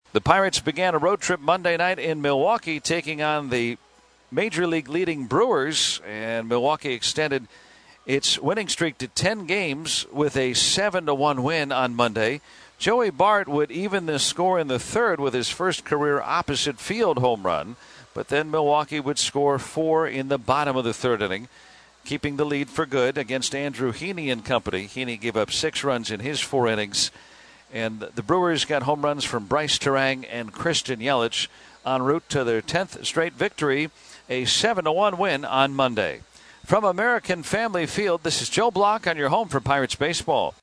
the recap of a loss to the red-hot Brewers